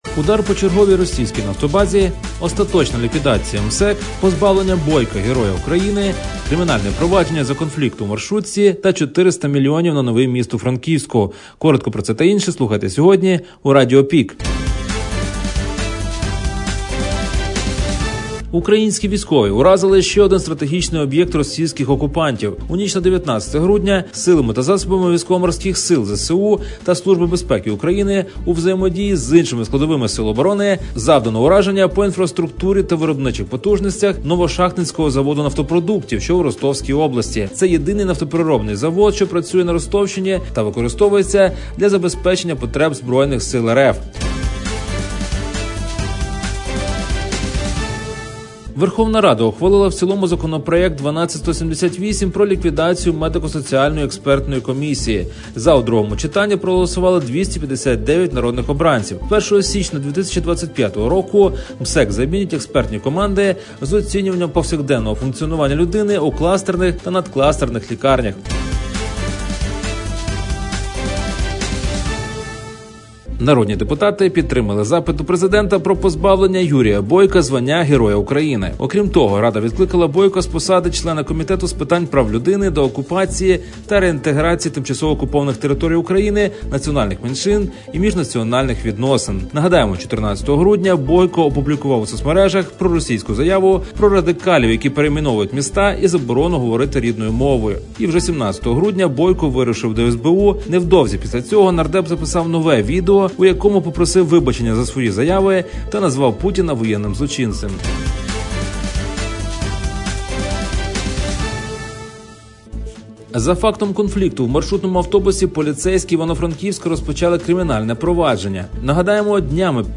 Пропонуємо актуальне за день у радіоформаті.